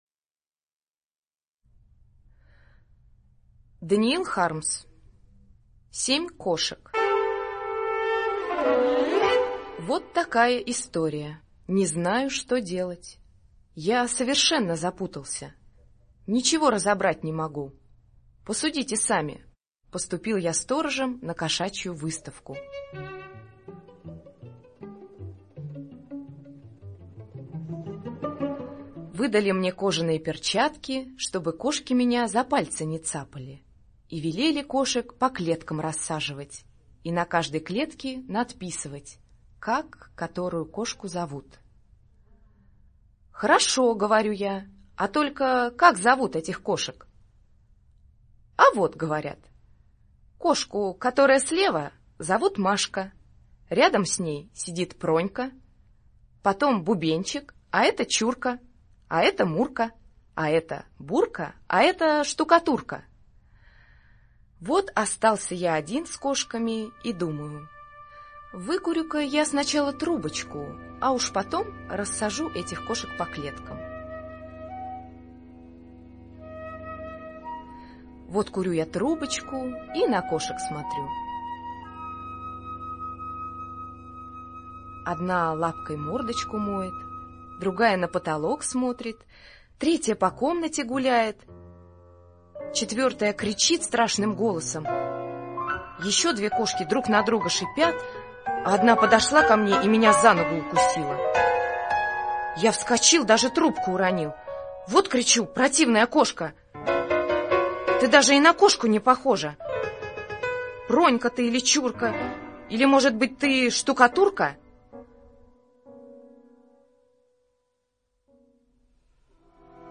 Аудиорассказ «Семь кошек»